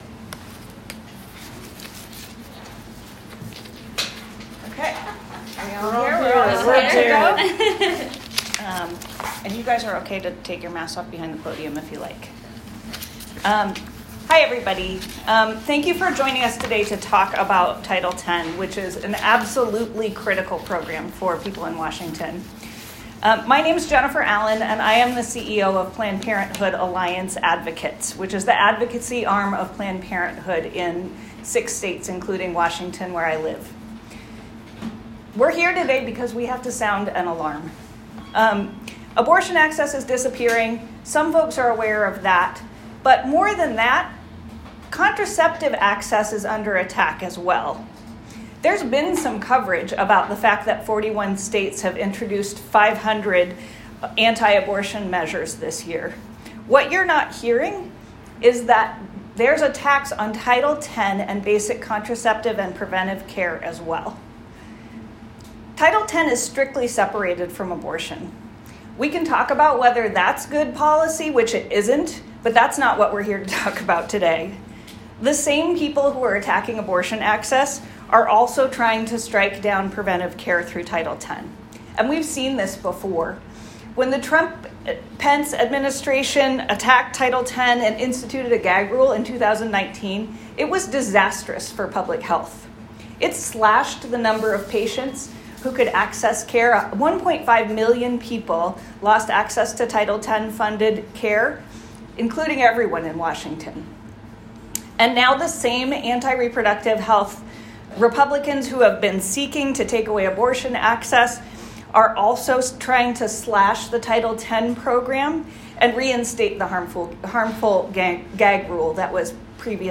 ***LISTEN: Senator Murray, advocates discuss fight to protect Title X***